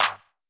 CLF Clap.wav